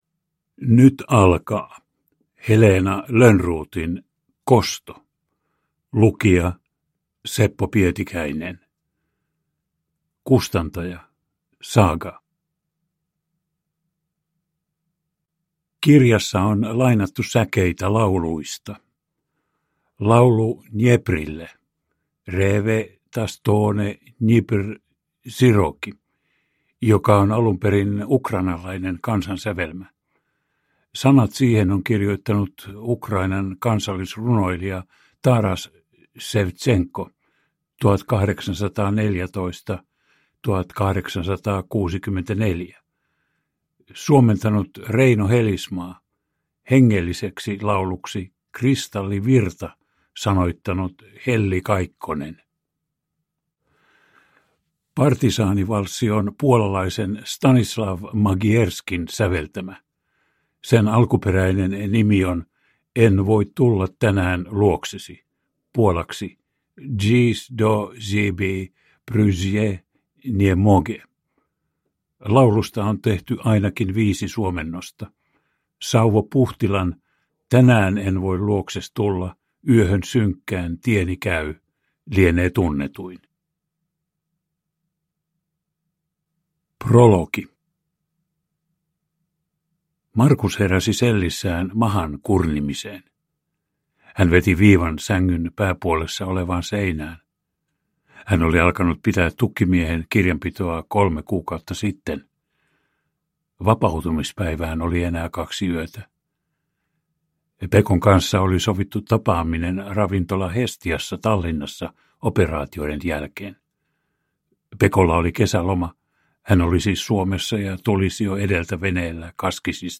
Kosto (ljudbok) av Heleena Lönnroth